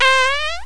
fart3.wav